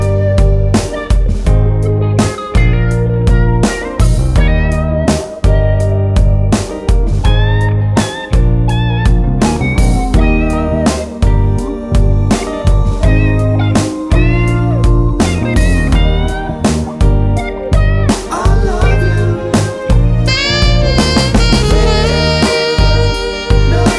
no Backing Vocals Soul / Motown 4:44 Buy £1.50